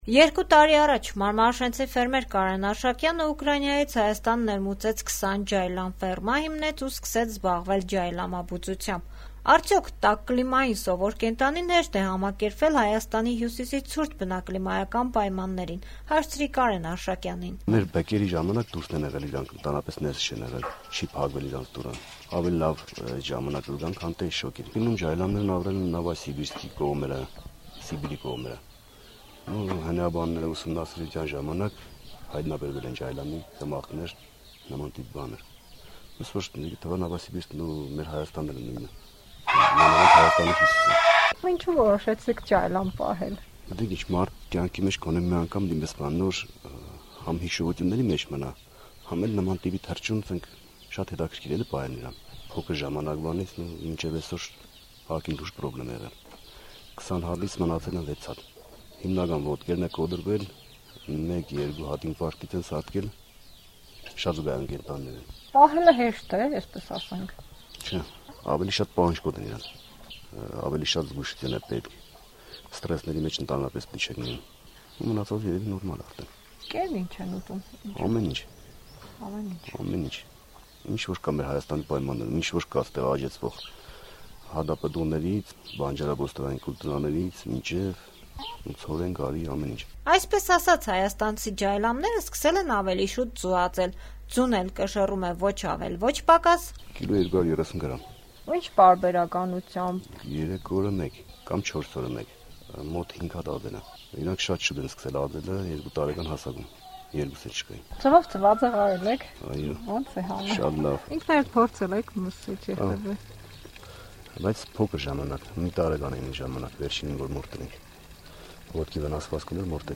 «Ազատություն» ռադիոկայանի հետ զրույցում